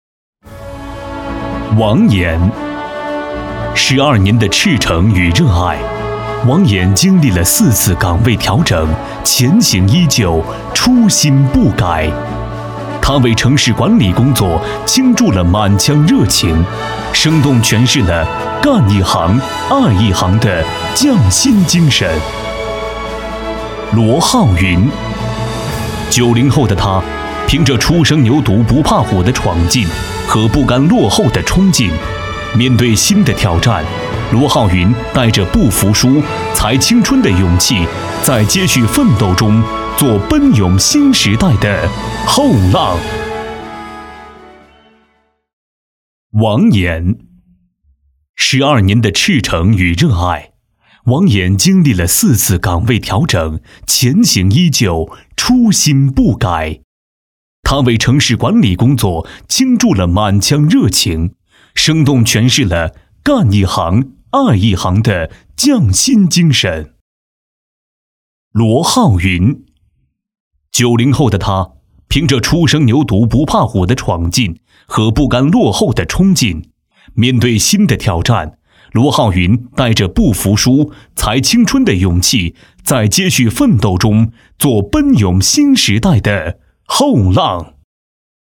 特点：年轻时尚 轻松幽默 MG动画
风格:欢快配音
31男127系列-【大气颁奖】—最美人物.mp3